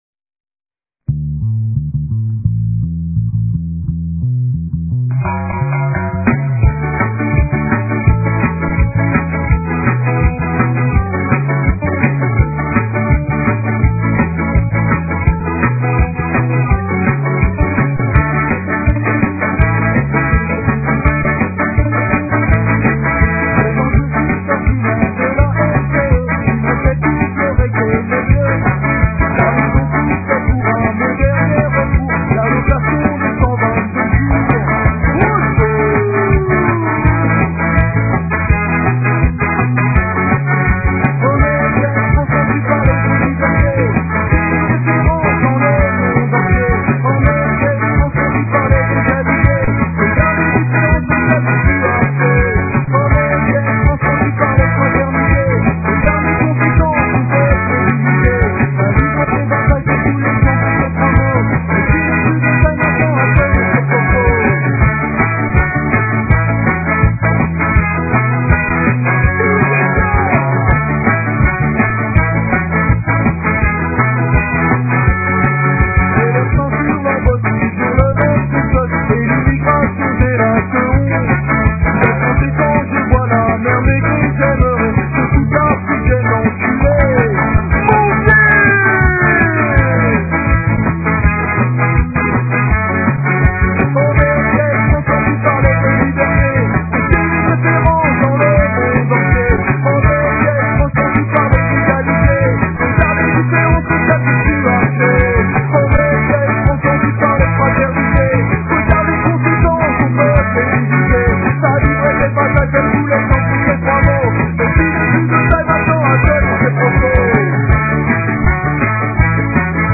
Les fichiers sont en mp3 et de qualité assez mauvaise .